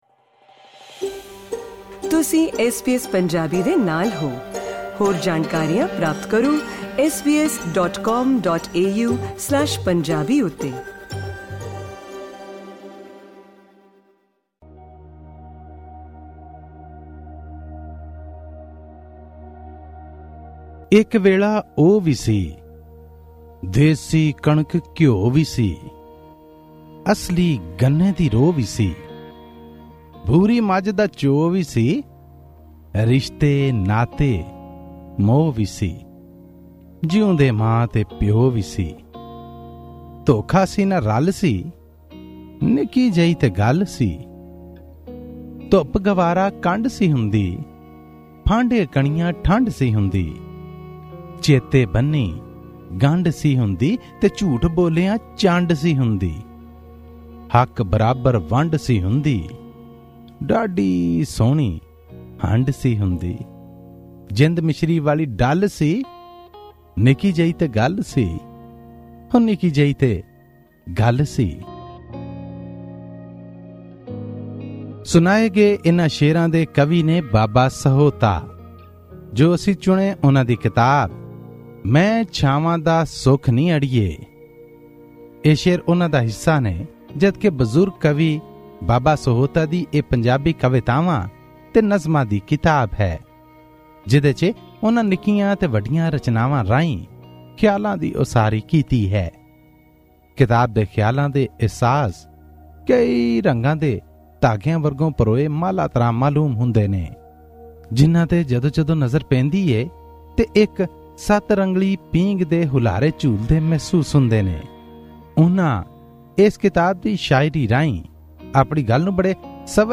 Punjabi book review: 'Main Shawan Di Sukh Ni Ariye' by Baba Sahouta